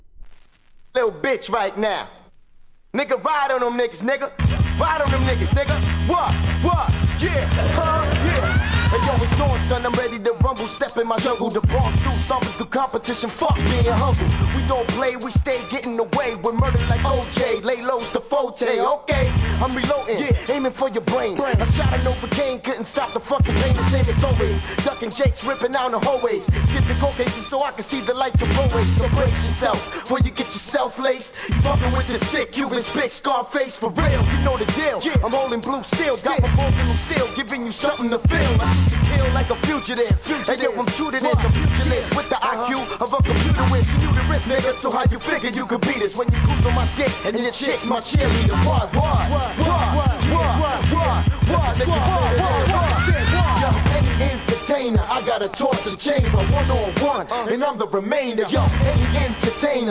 HIP HOP/R&B
ラティーノアーティストによるフリースタイル集！